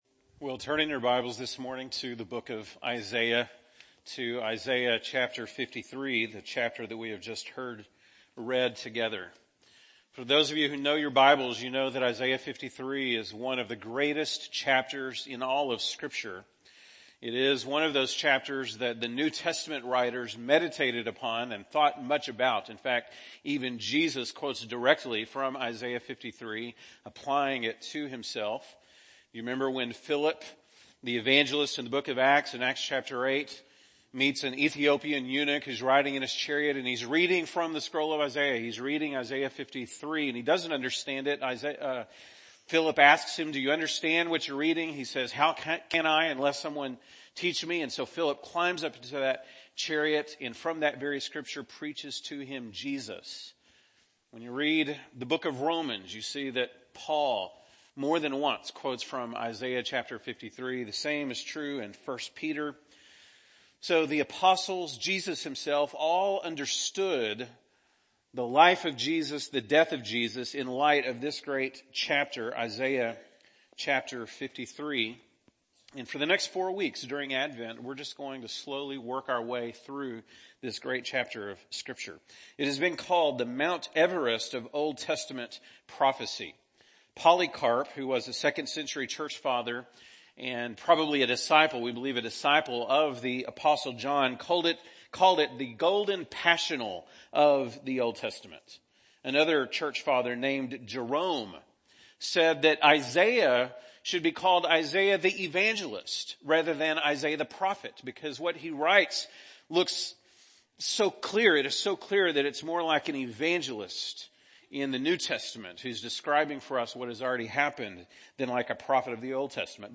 December 1, 2019 (Sunday Morning)